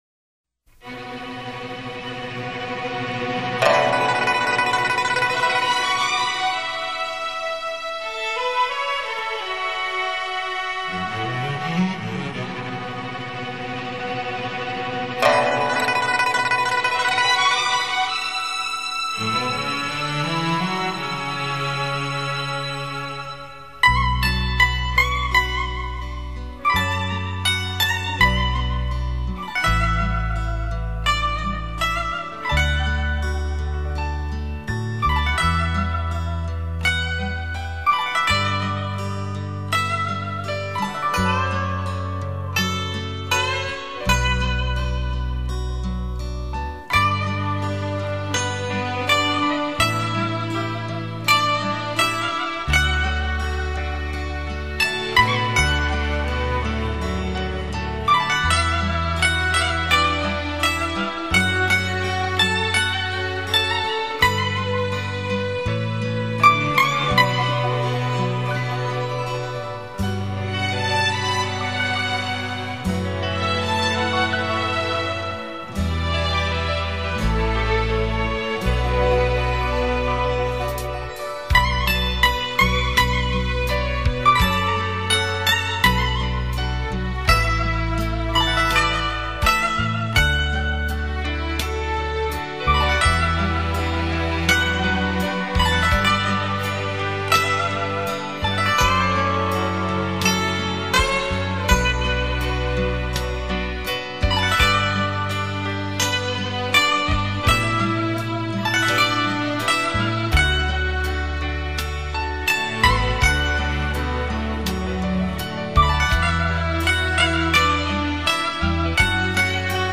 （梦与诗/古筝） 激动社区，陪你一起慢慢变老！